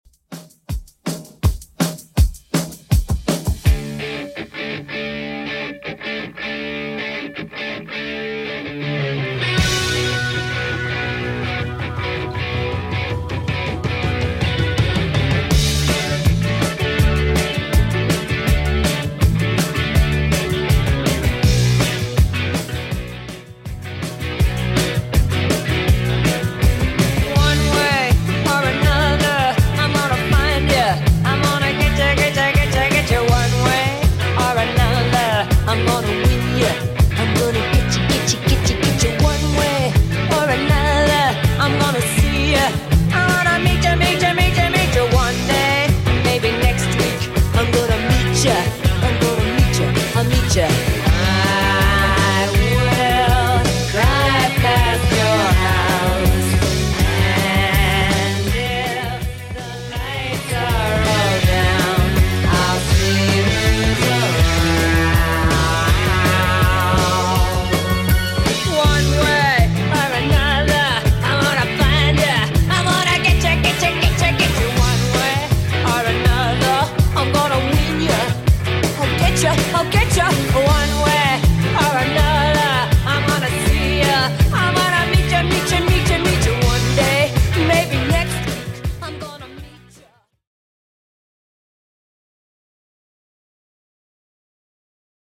Genre: 80's
BPM: 123